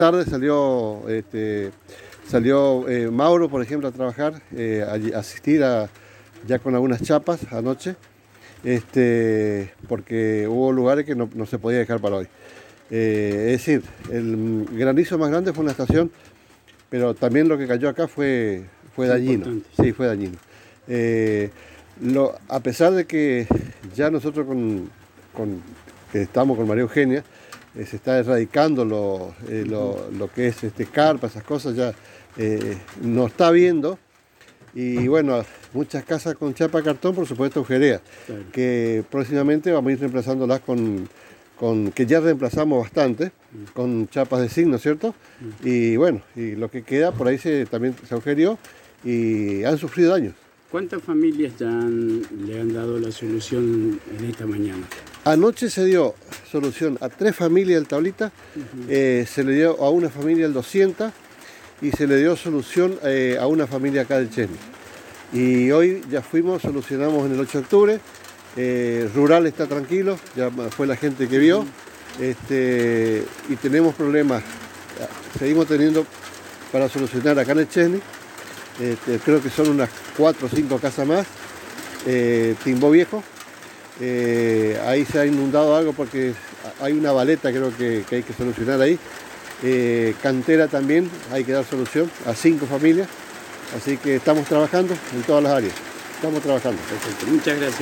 El funcionario Municipal comentó en exclusiva a la ANG que están trabajando en solucionar los problemas ocasionados a las familias lo más pronto posible.